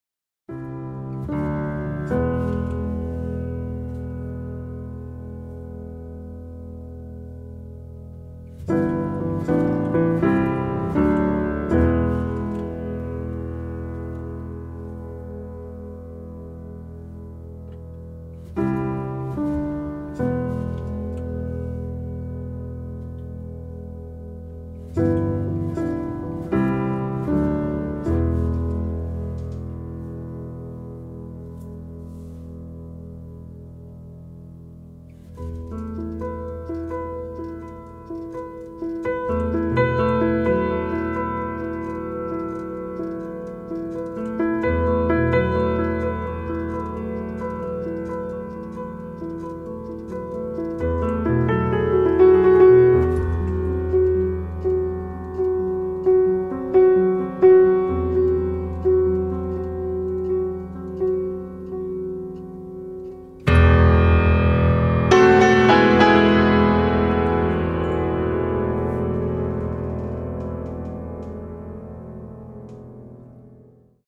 Каталог -> Джаз и около -> Тихие эмоции
это музыка, сыгранная на рояле.